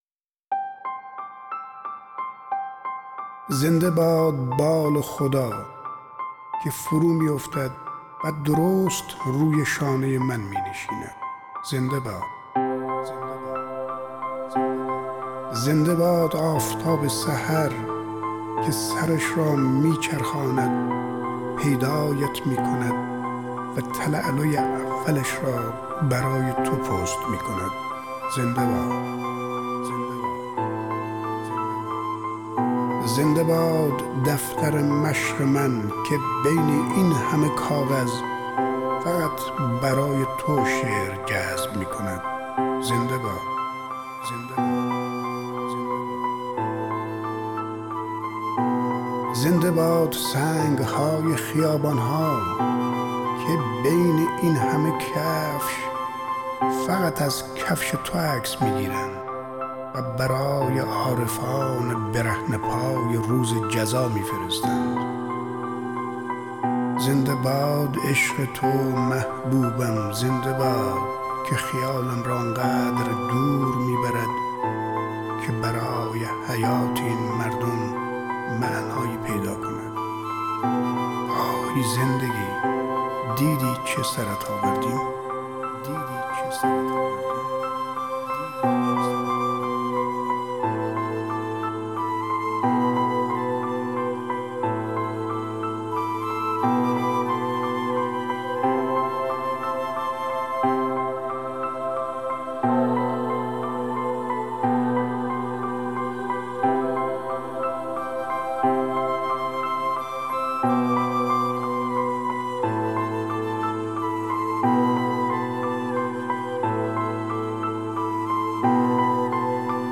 دانلود دکلمه زنده باد بال خدا با صدای شمس لنگرودی
گوینده :   [شمس لنگرودی]